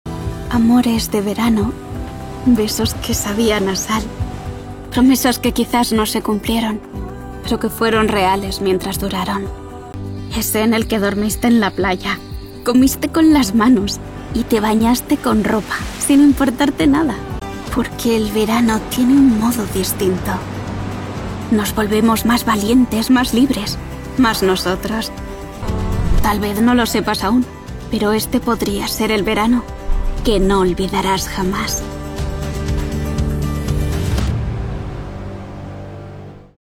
Chaleureux
Amical